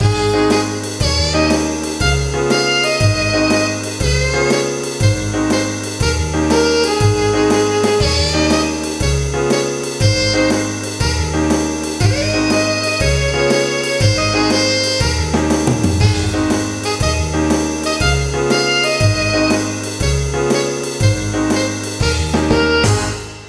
kirakirajazz1.wav